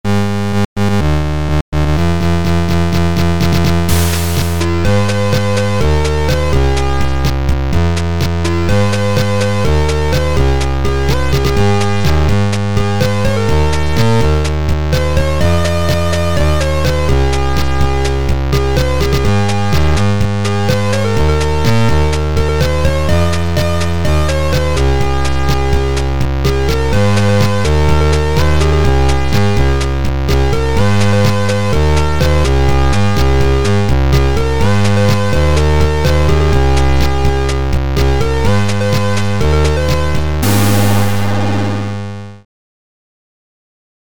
C64 version